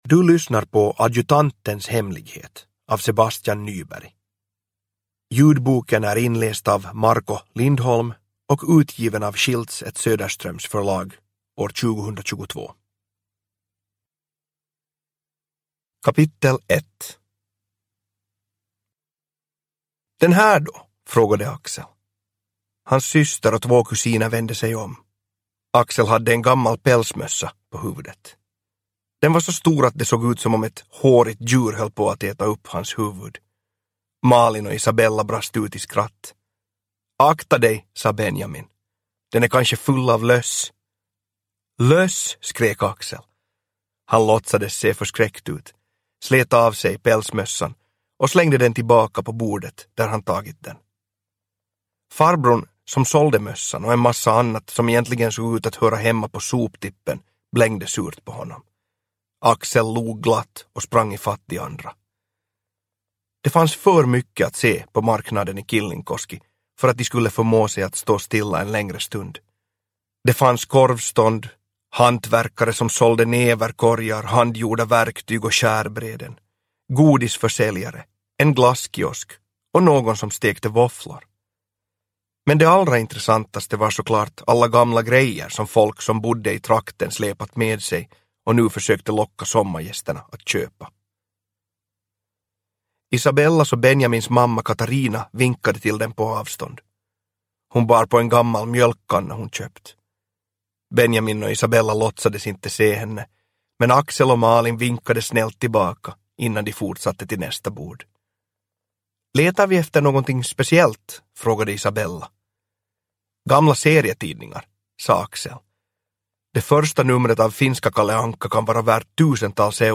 Adjutantens hemlighet – Ljudbok – Laddas ner